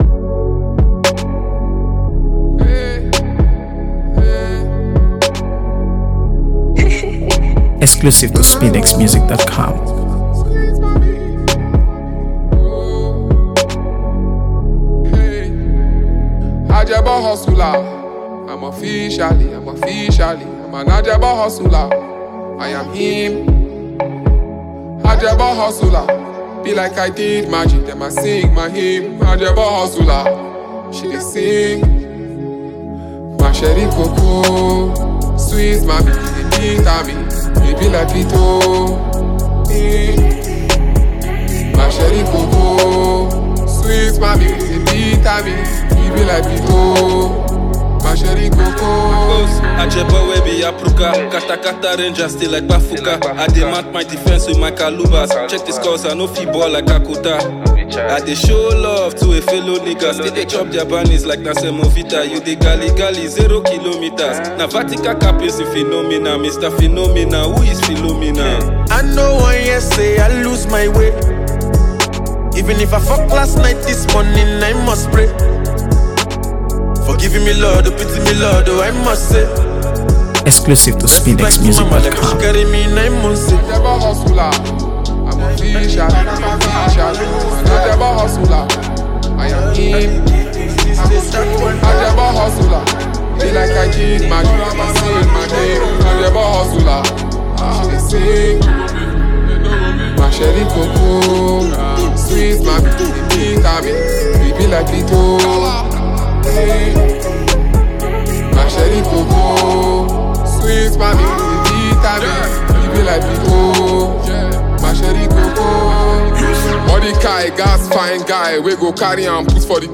AfroBeats | AfroBeats songs
layered production and an unmistakable bounce.